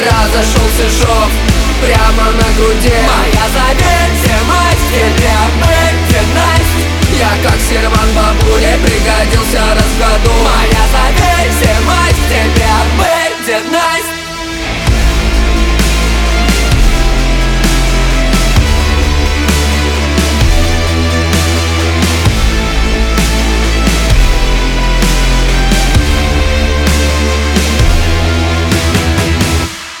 грустные
атмосферные
Alternative Rock